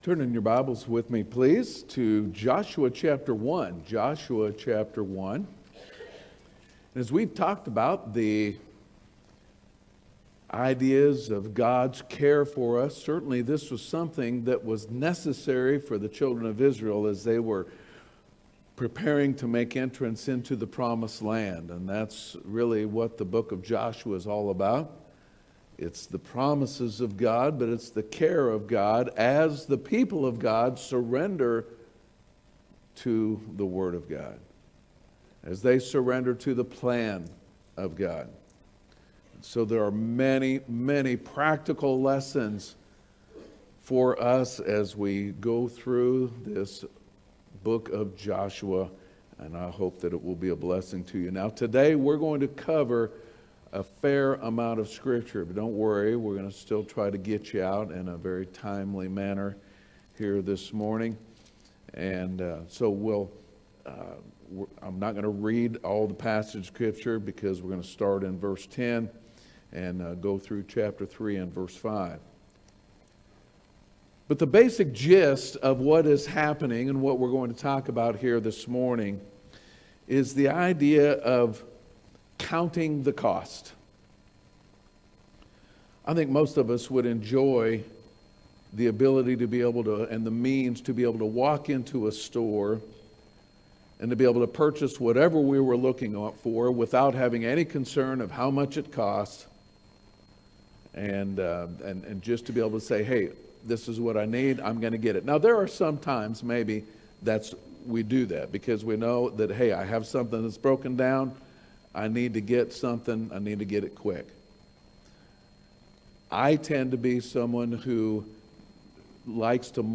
Our latest Sunday morning worship service Messages from God’s word